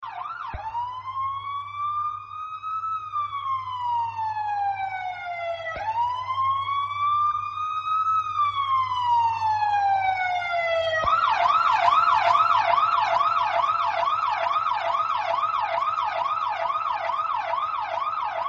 Police Siren Sound Effect - Sound Effect Button